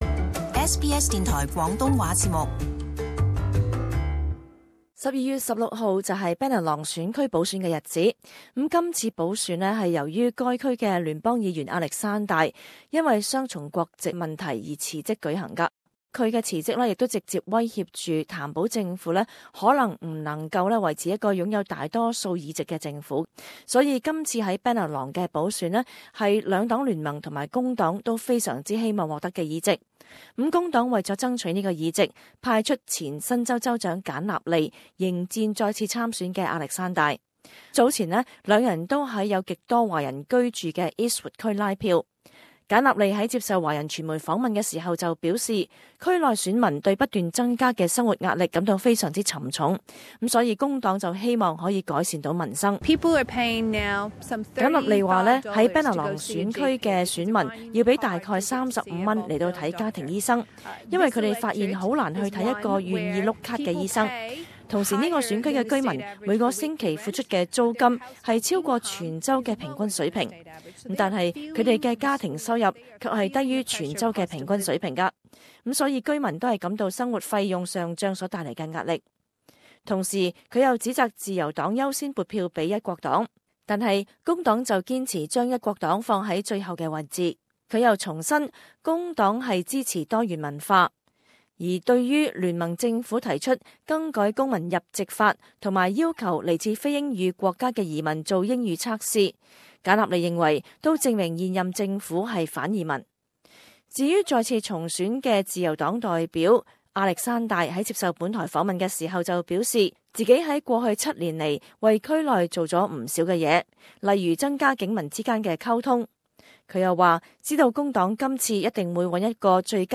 【时事专访】新州Bennelong补选形势透视